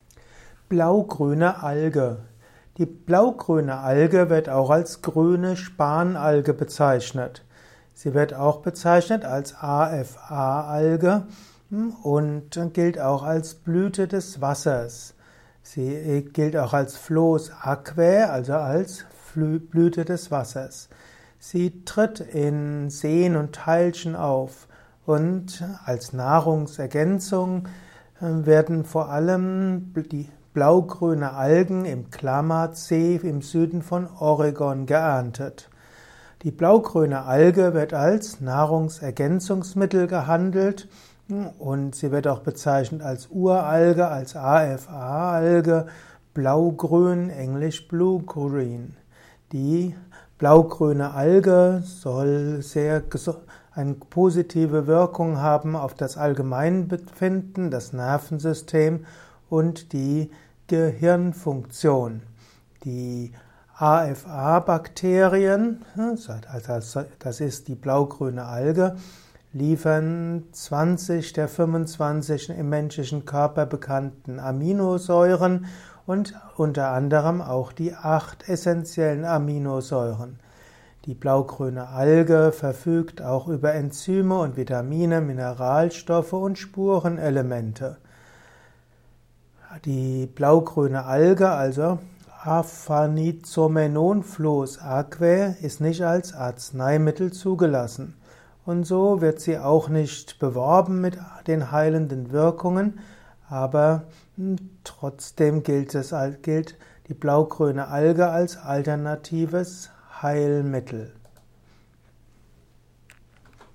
Kompakte Informationen zur blaugrünen Alge in diesem Kurzvortrag